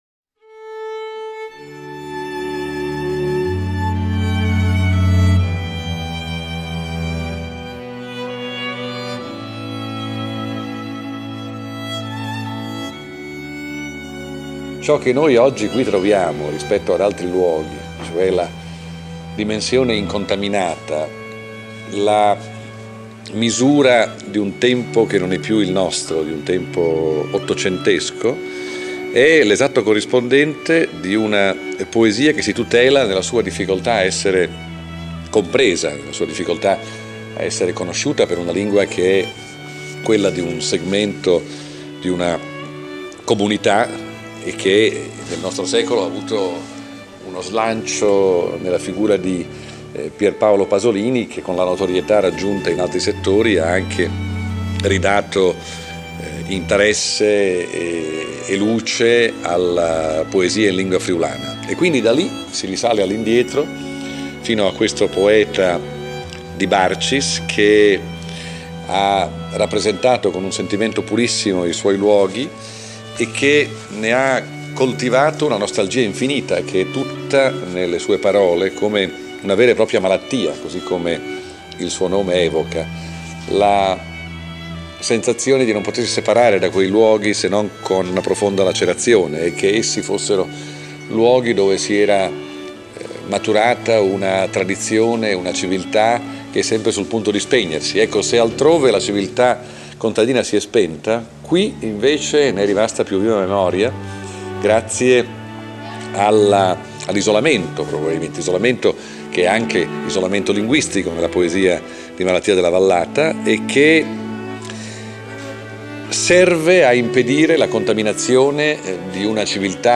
Premio Giuseppe Malattia: Presentazione Sgarbi
presentazione_sgarbi.mp3